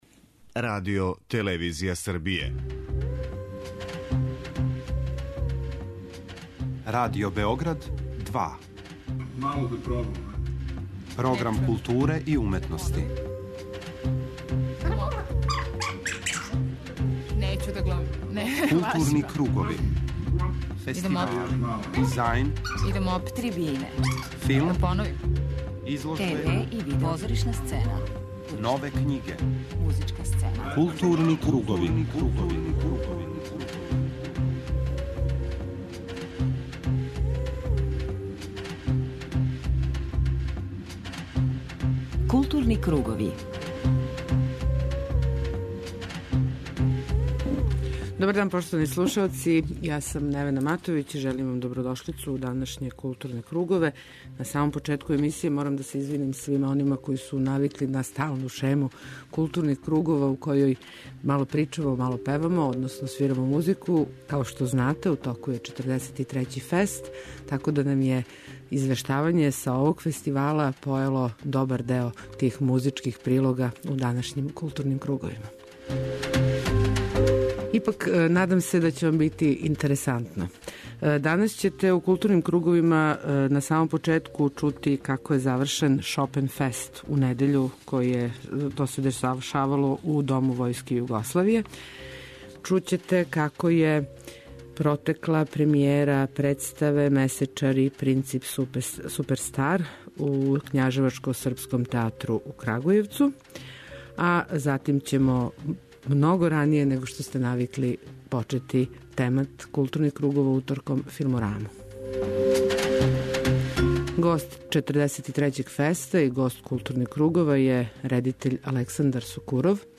Чућете разговор са руским редитељем Александром Сокуровим, добитником новоустановљене фестове награде Београдски победник за изузетан допринос филмској уметности.